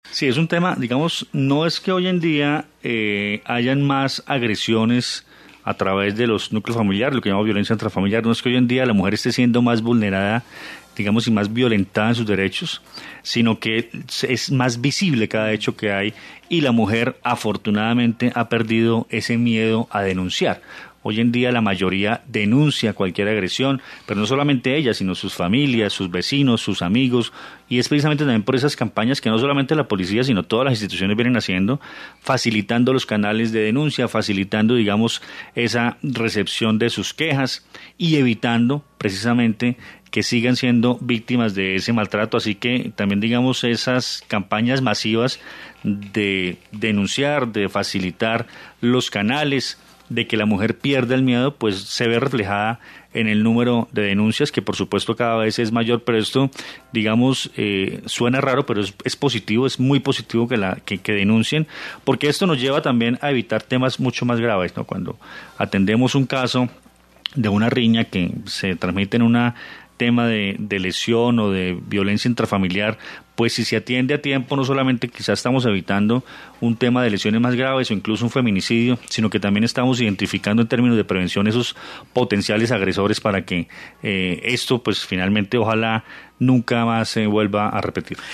Coronel Luis Fernando Atuesta, comandante Policía, Quindío